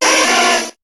Cri de Salamèche dans Pokémon HOME.